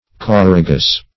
Choragus \Cho*ra"gus\, n.; pl. Choragi.